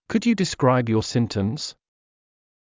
ｸｯ ｼﾞｭｰ ﾃﾞｨｽｸﾗｲﾌﾞ ﾕｱ ｼﾝﾌﾟﾄﾑｽﾞ